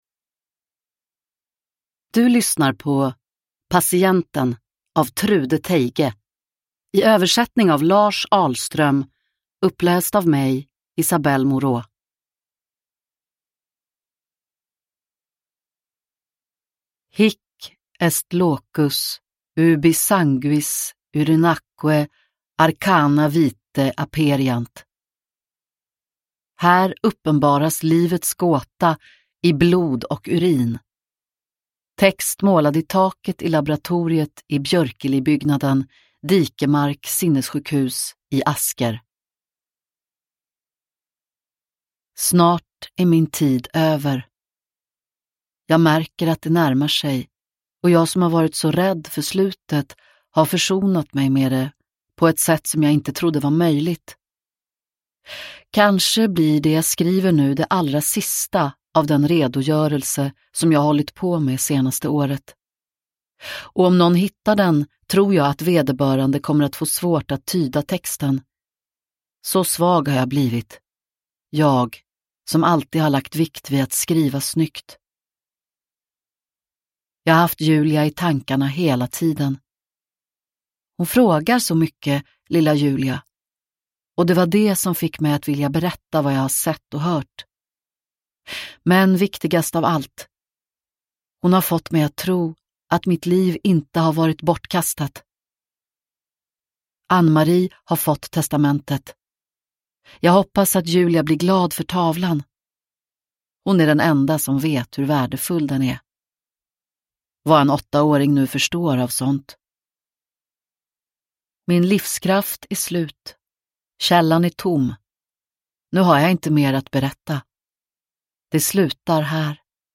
Patienten – Ljudbok – Laddas ner